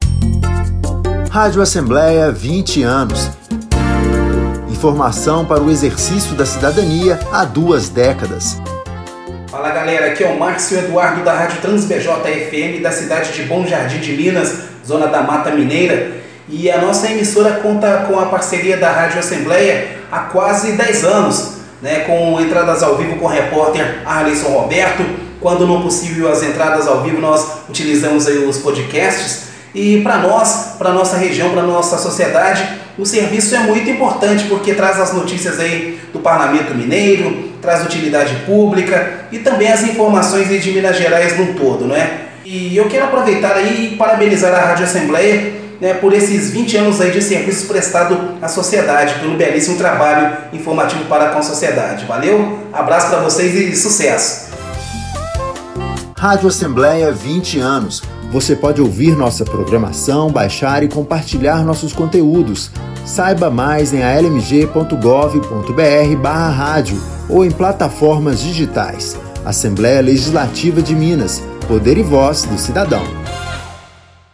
Campanhas educativas e institucionais